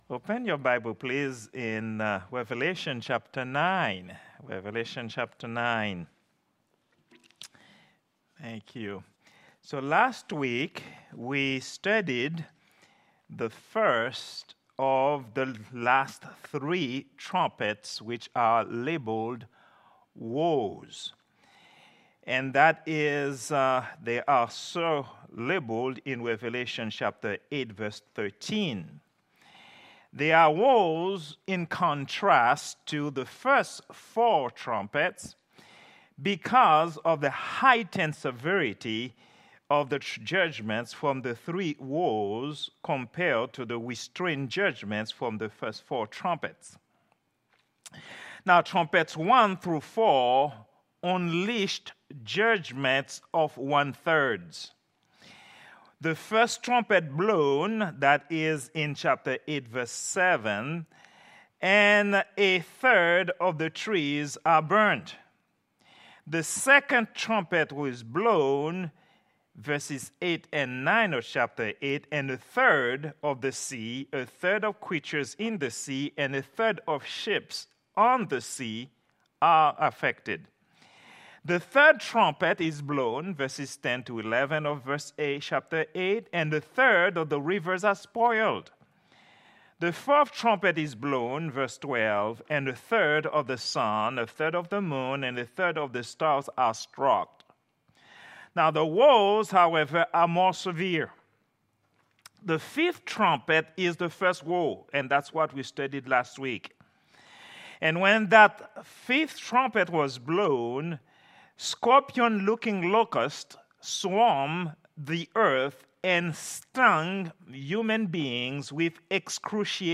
Prayer_Meeting_09_18_2024.mp3